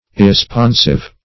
Meaning of irresponsive. irresponsive synonyms, pronunciation, spelling and more from Free Dictionary.
Search Result for " irresponsive" : The Collaborative International Dictionary of English v.0.48: Irresponsive \Ir`re*spon"sive\, a. Not responsive; not able, ready, or inclined to respond.